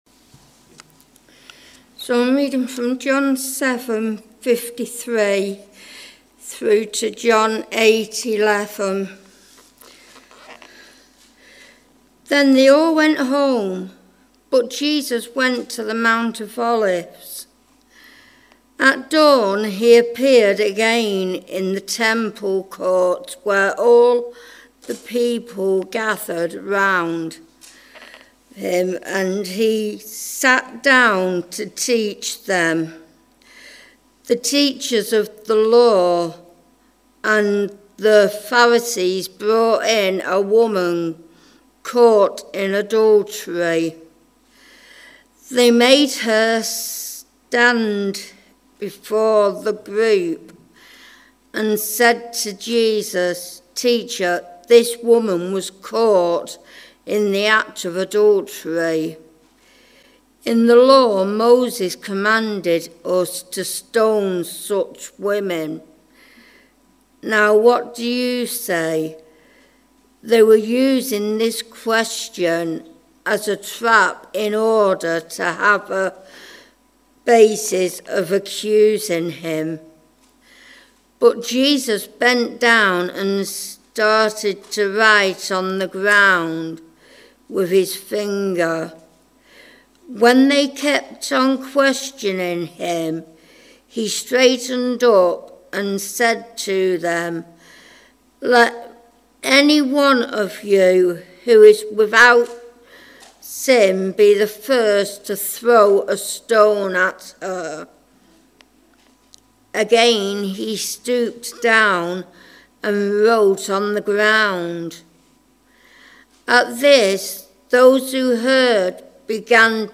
Talks - WSCF